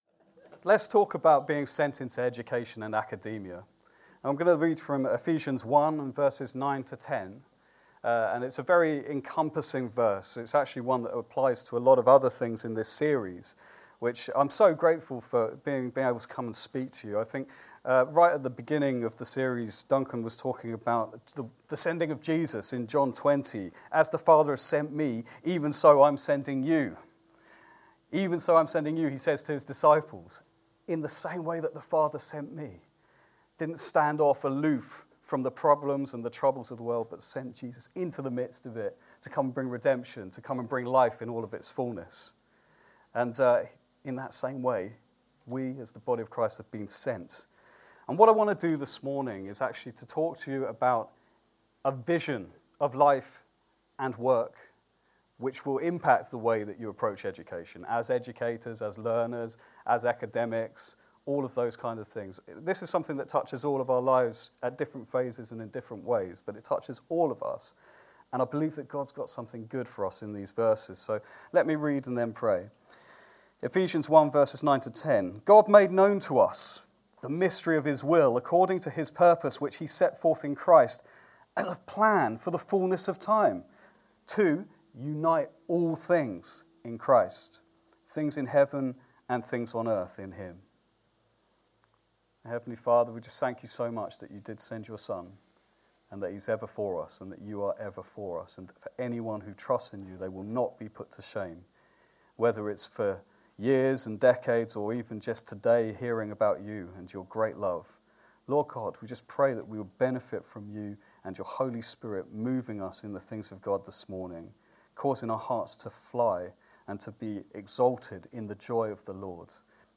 Download Sent into Education and Academia | Sermons at Trinity Church